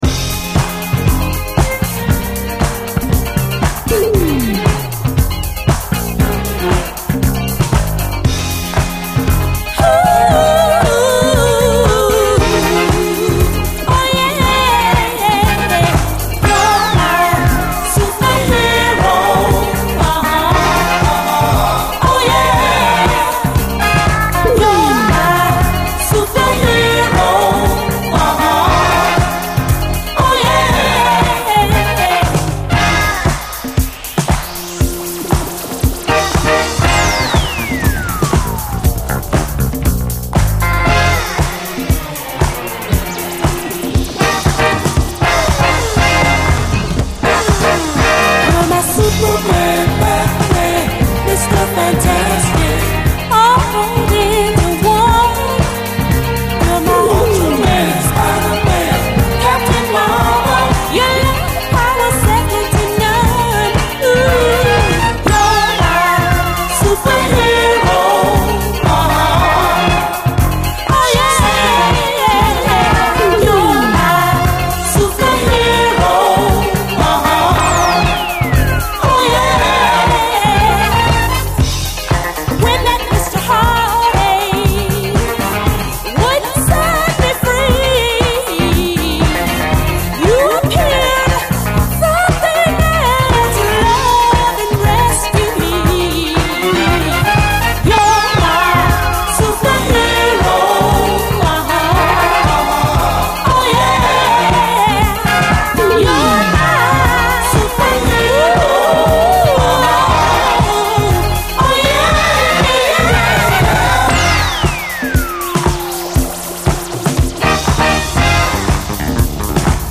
SOUL, 70's～ SOUL, DISCO
デトロイト産マイナー・モダン・ブギー！